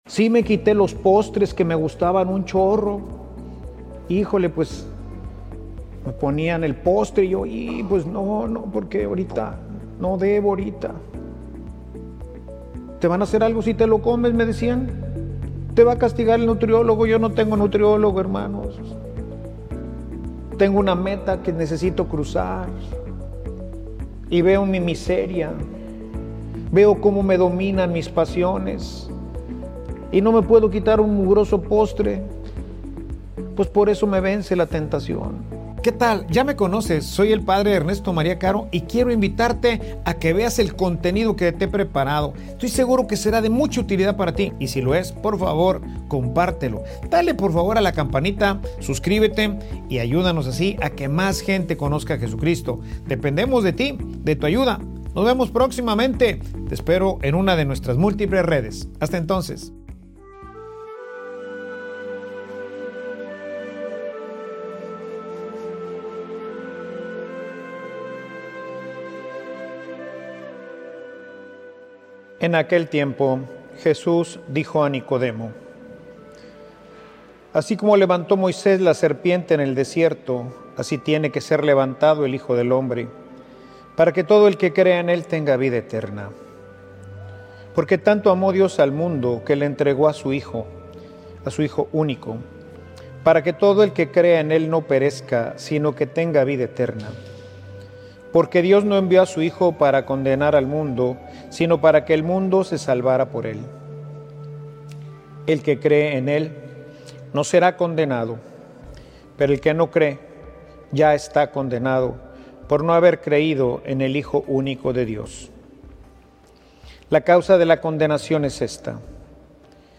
Homilia_Vas_hacia_donde_quieres_llegar.mp3